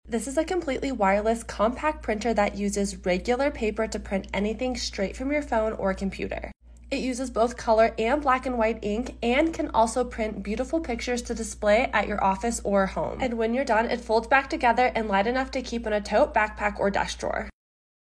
COMPACT, lightweight, & wireless printer sound effects free download